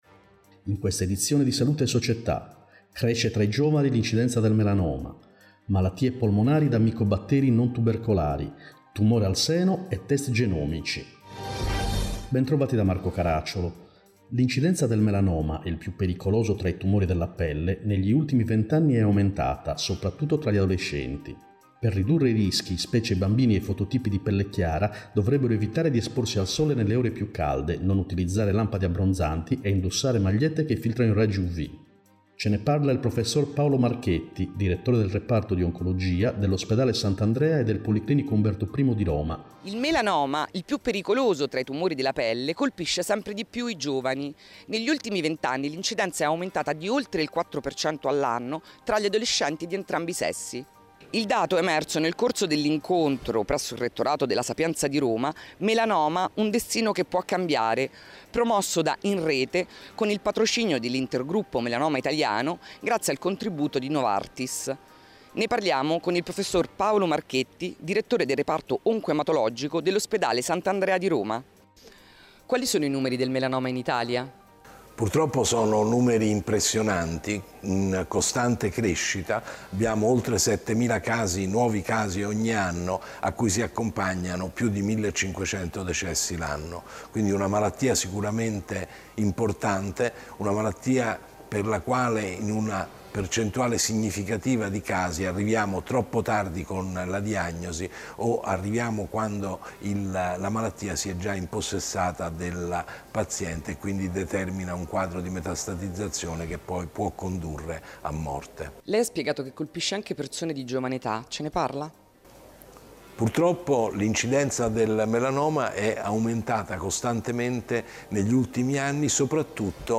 L’incidenza del melanoma cresce tra i giovani Terapia delle malattie polmonari da micobatteri non tubercolari Tumore al seno e test genomici: chi deve farli e a che cosa servono Interviste